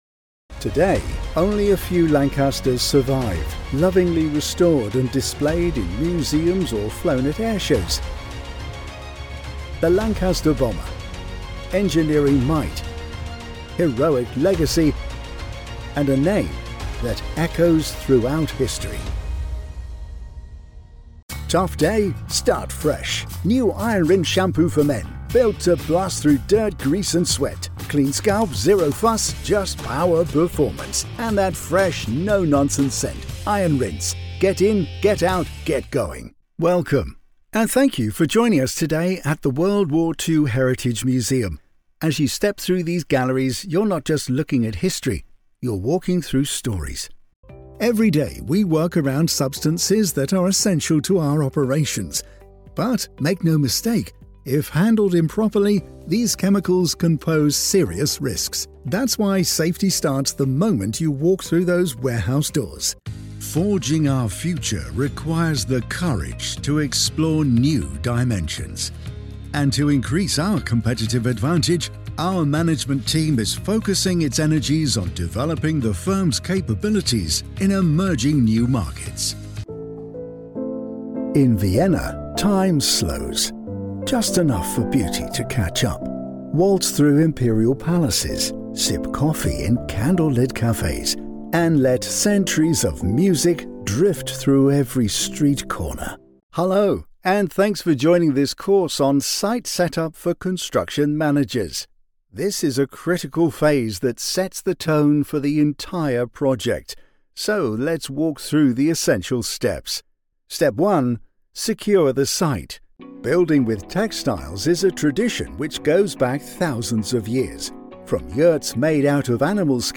Older Sound (50+)